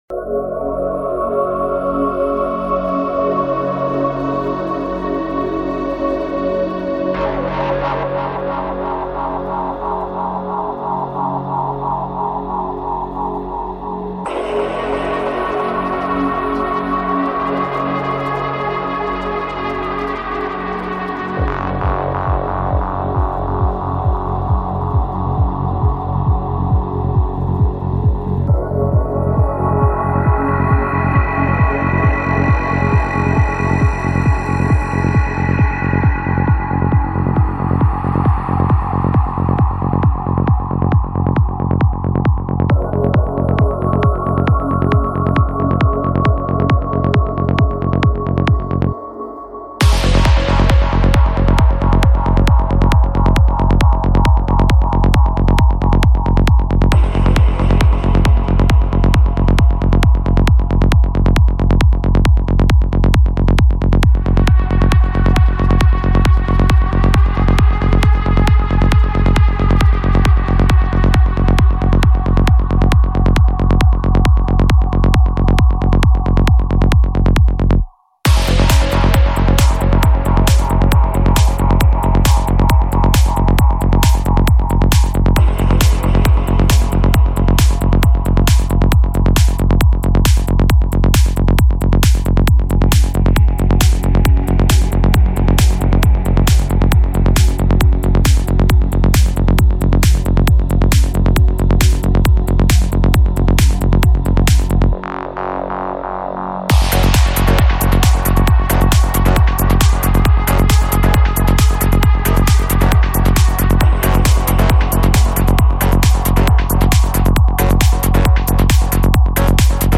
Жанр: Psychedelic
Альбом: Psy-Trance